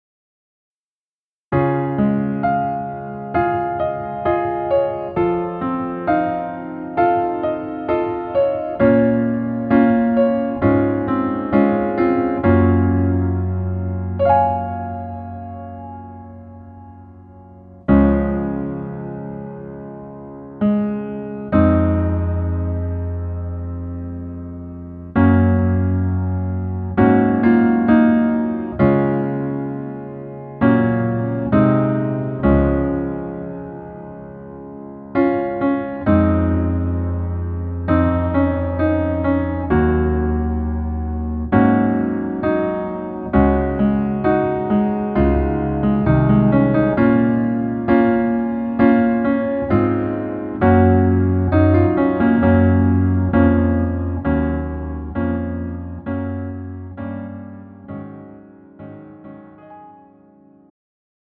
음정 -1키
장르 가요 구분 Pro MR
Pro MR은 공연, 축가, 전문 커버 등에 적합한 고음질 반주입니다.